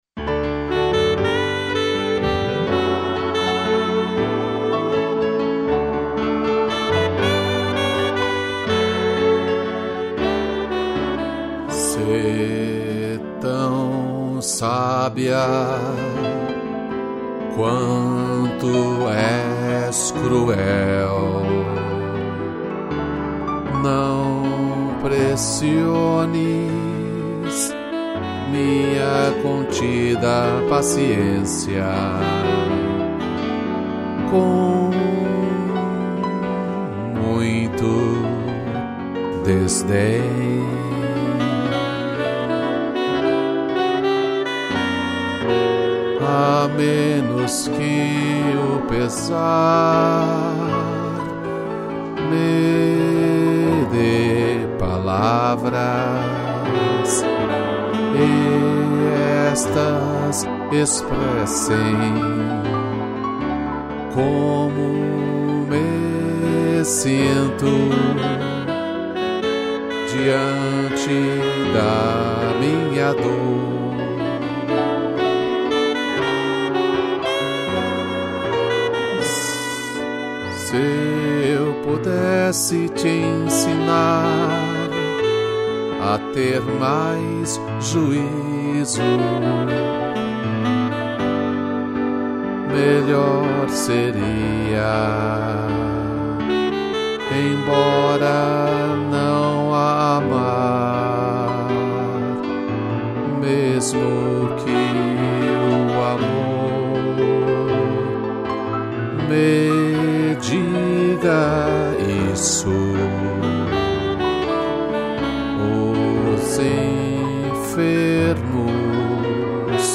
2 pianos, sax e órgão